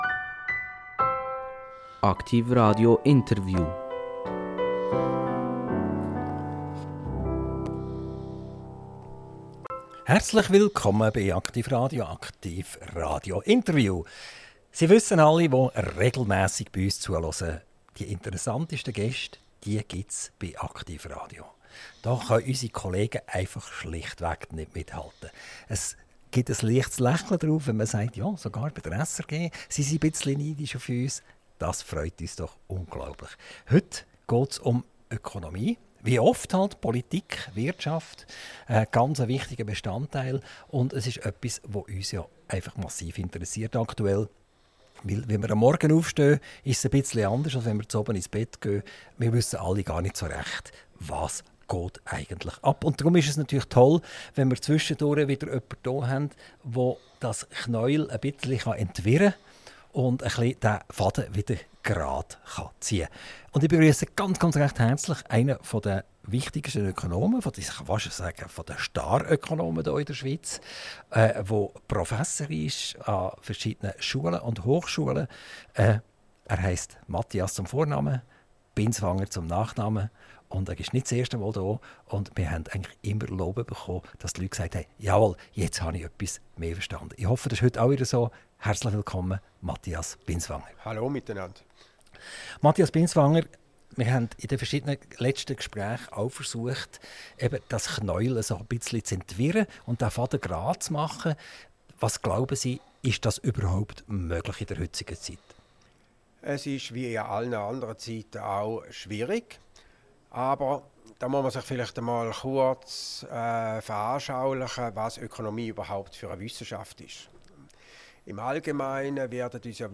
INTERVIEW - Mathias Binswanger - 07.10.2025 ~ AKTIV RADIO Podcast